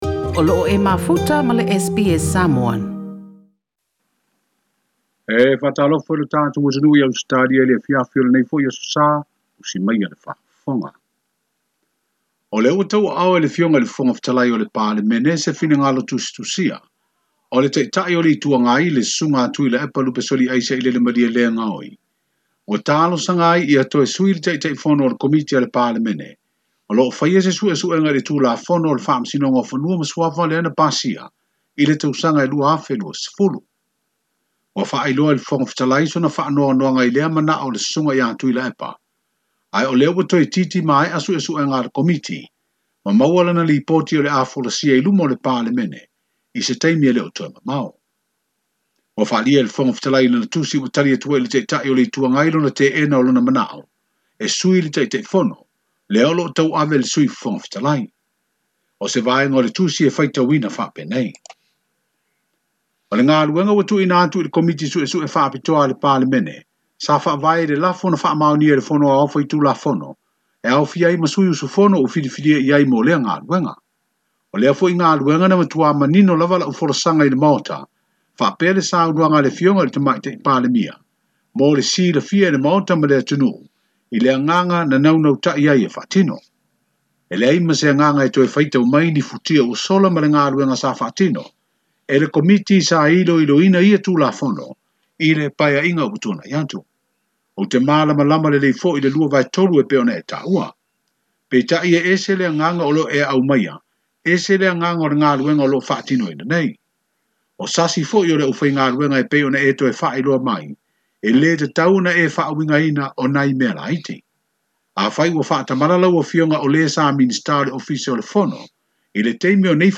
I le ripoti o talafou mai Apia i lenei afiafi, o loo aofia ai ma se vaega o le tali a le fofoga fetalai o le palemene, Papali'i Li'o Ta'eu Masipau i le ta'ita'i o le ituagai Tuila'epa Sailele Malielegaoi i le mataupu i le su'esu'ega faapalemene o le faamasinoga o fanua ma suafa.